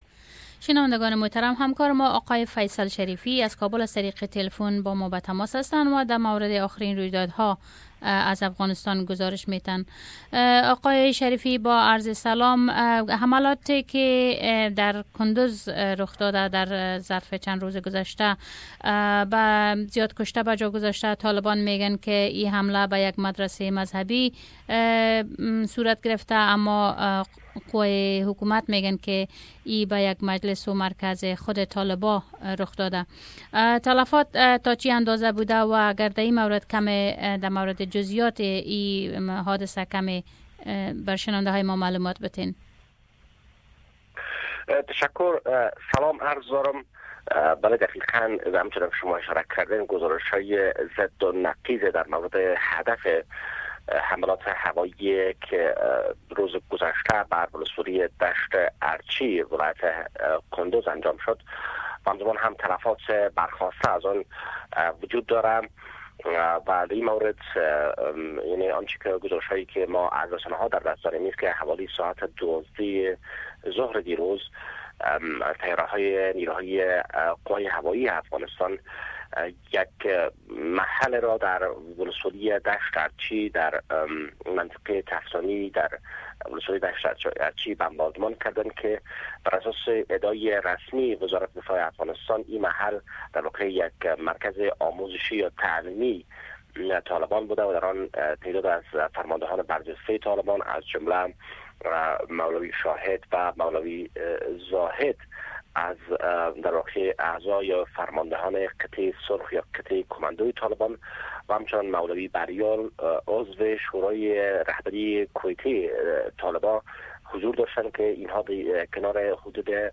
Report From Kabul